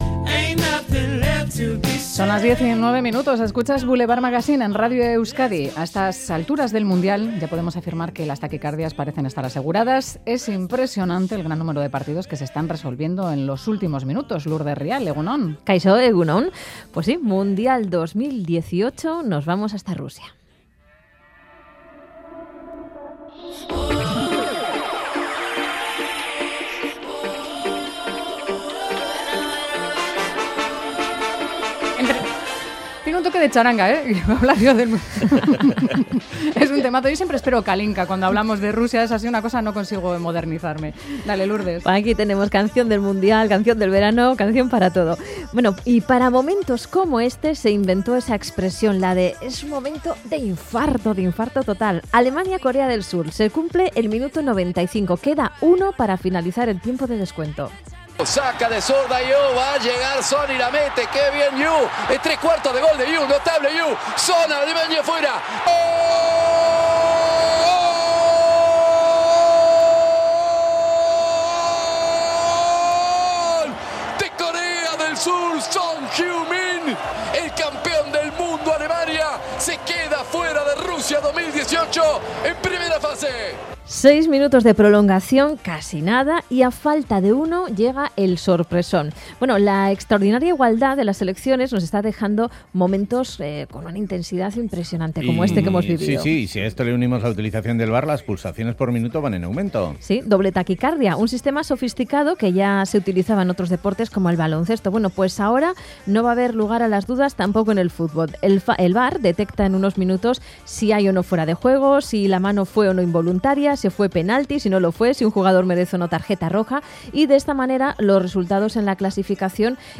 nos explica en una divertida charla cómo se usará. Le planteamos situaciones concretas que podrían darse en cualquier partido...